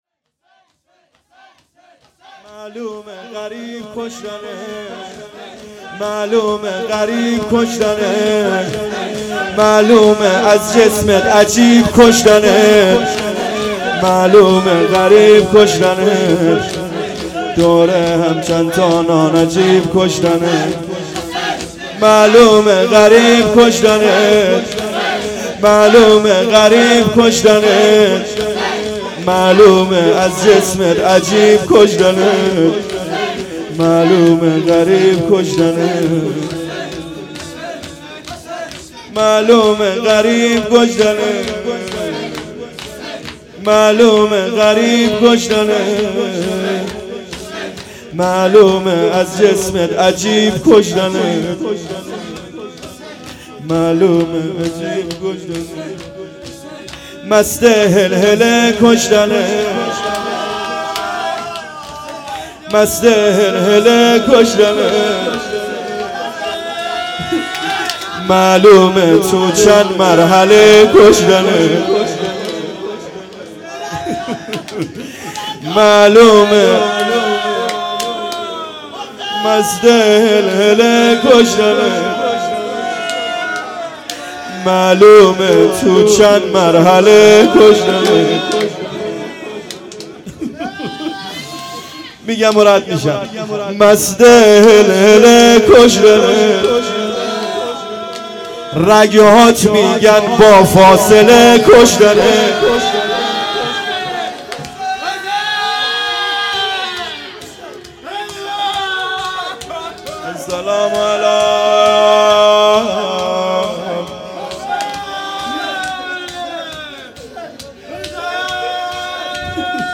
هيأت یاس علقمه سلام الله علیها
معلومه غریب کشتنت _ شور
شب نهم محرم الحرام 1441